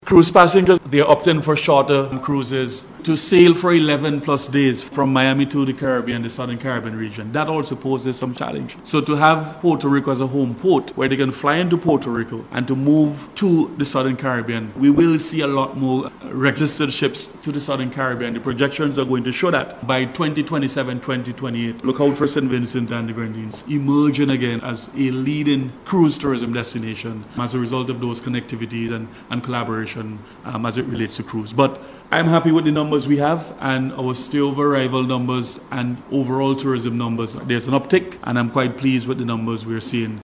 That’s according to Minister of Tourism, Carlos James who was speaking during a joint media briefing hosted in collaboration with the Carnival Development Corporation (CDC), yesterday.